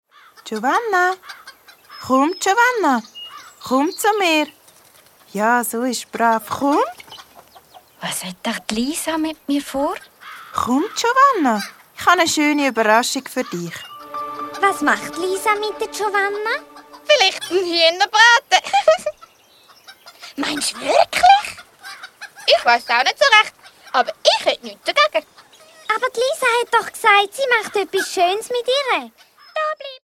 Das letzte Huhn Das Hörspiel nach dem Bilderbuch Das letzte Huhn.